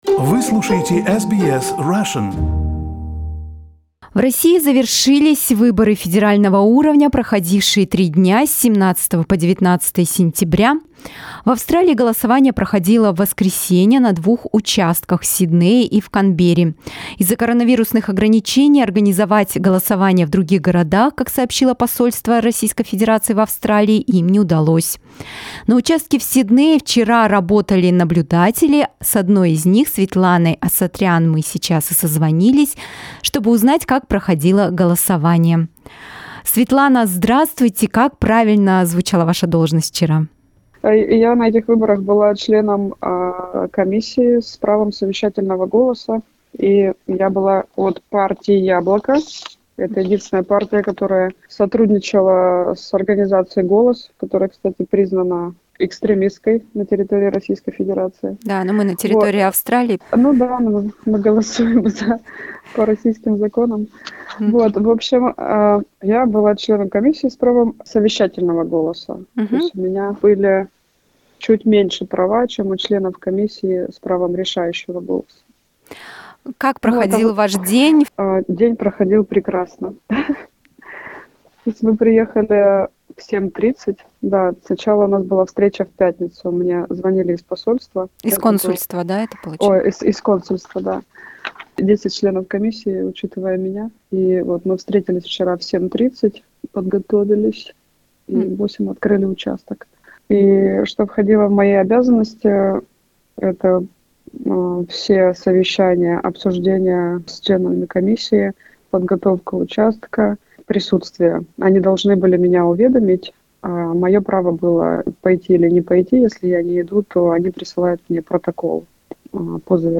Duma elections at the Consulate of the Russian Federation in Sydney: interview with an observer